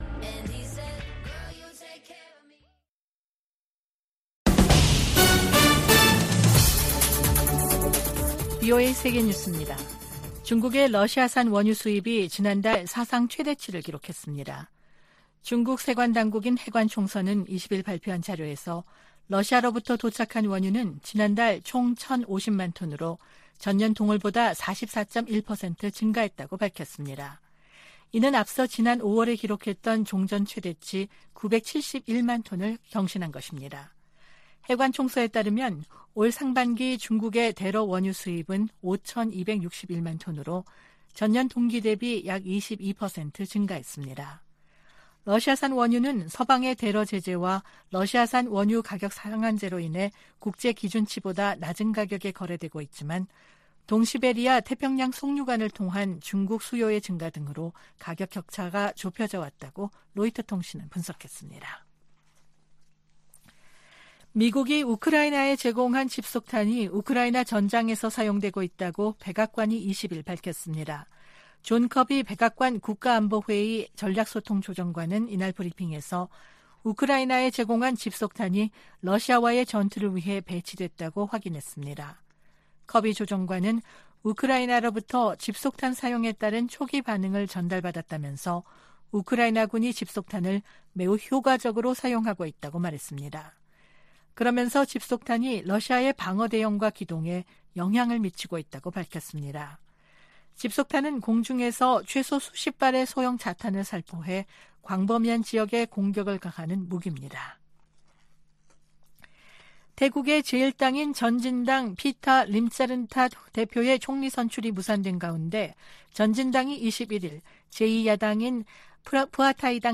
VOA 한국어 아침 뉴스 프로그램 '워싱턴 뉴스 광장' 2023년 7월 22일 방송입니다. 백악관은 월북 미군 병사의 안전과 소재 파악에 최선을 다하고 있지만 현재 발표할 만한 정보는 없다고 밝혔습니다. 미국과 한국의 핵협의그룹(NCG)을 외교・국방 장관 참여 회의체로 격상하는 방안이 미 상원에서 추진되고 있습니다. 미 국방부가 전략핵잠수함(SSBN)의 한국 기항을 비난하며 핵무기 사용 가능성을 언급한 북한의 위협을 일축했습니다.